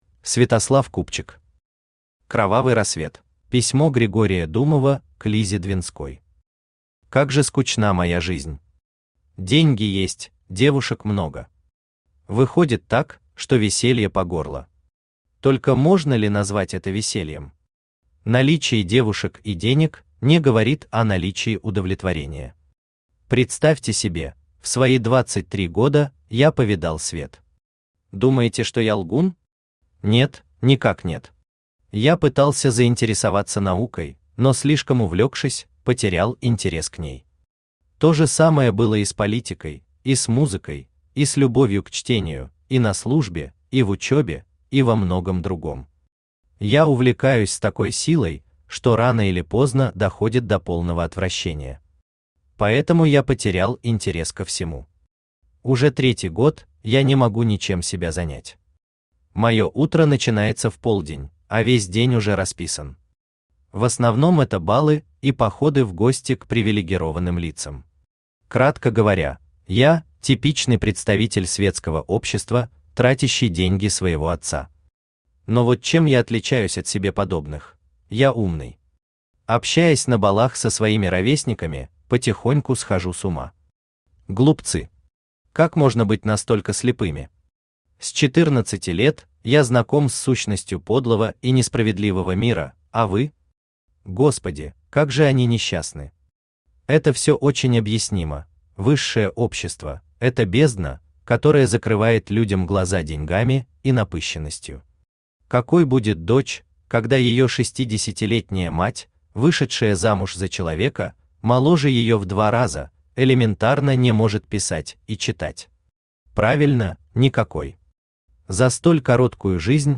Аудиокнига Кровавый рассвет | Библиотека аудиокниг
Aудиокнига Кровавый рассвет Автор Святослав Купчик Читает аудиокнигу Авточтец ЛитРес.